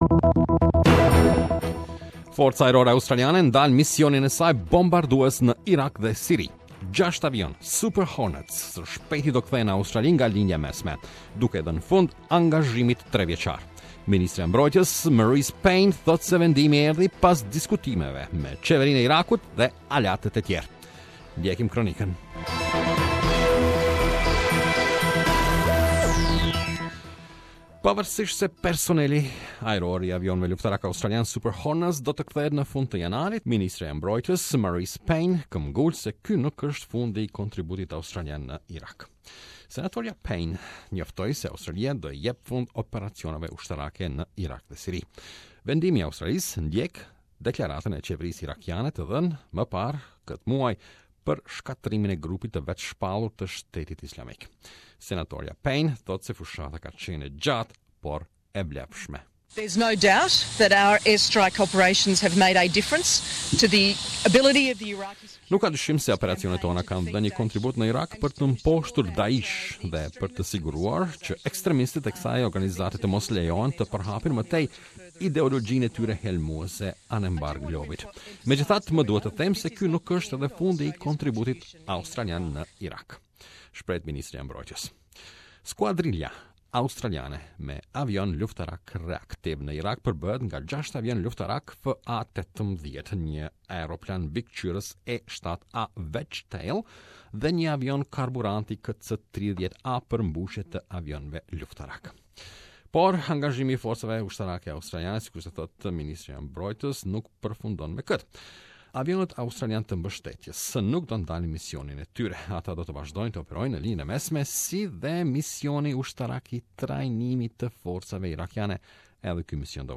Defence Minister Marise (muh-reece) Payne says the decision follows discussions with the Iraqi government and other allies.